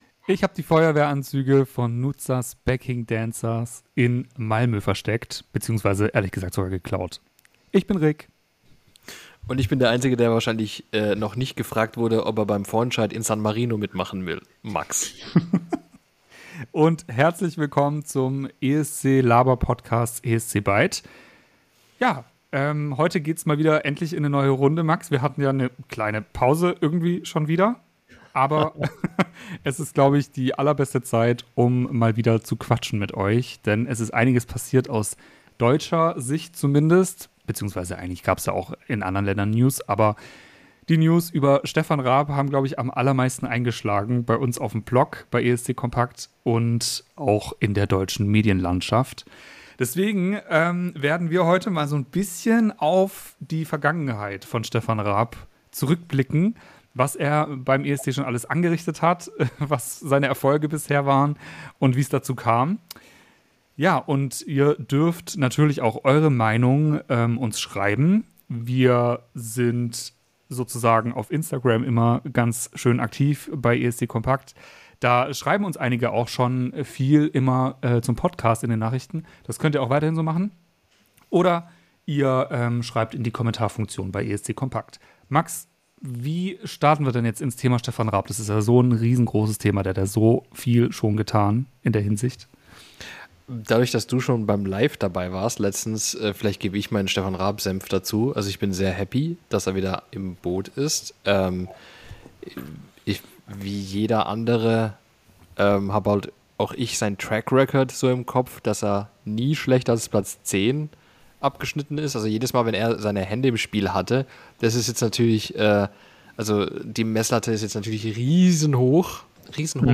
Der Laber-Podcast zum Eurovision Song Contest